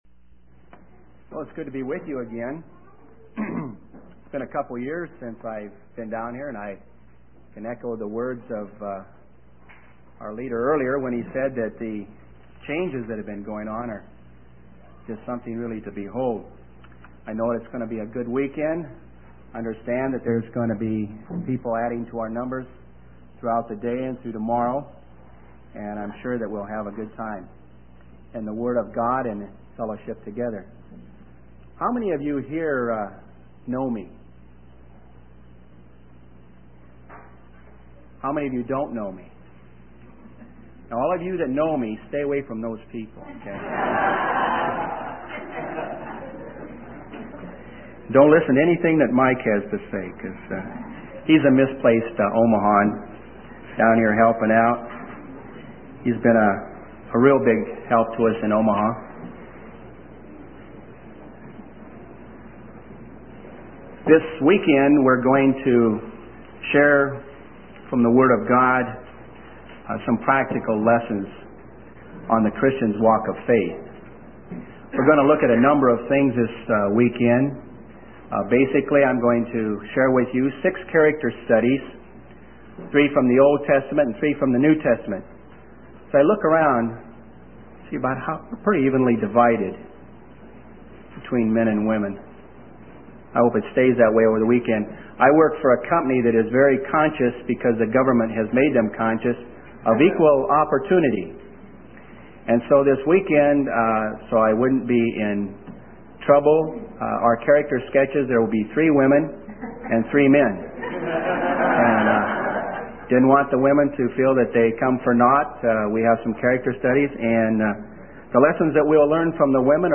In this sermon, the preacher emphasizes the importance of having a strong foundation in the Word of God.